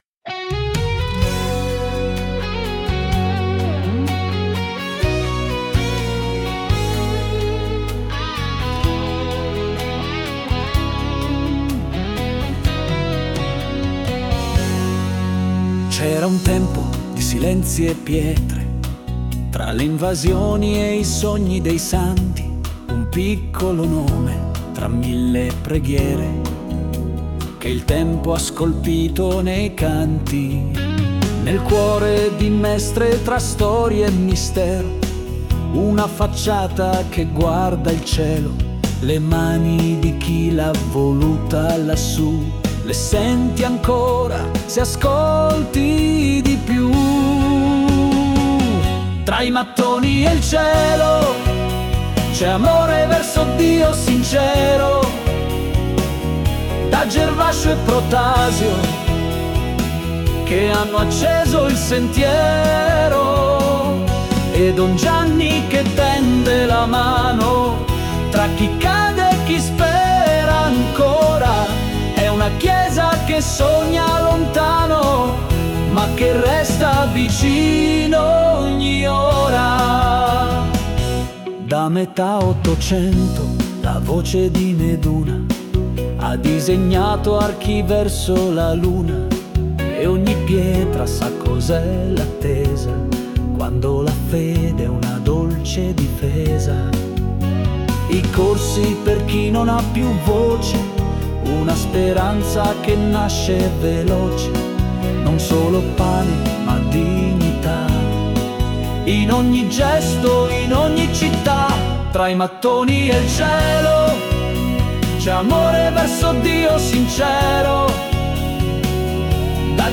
Melodia, esecuzione e canto sono stati creati con l'intelligenza artificiale, per l'esattezza sul sito Suno.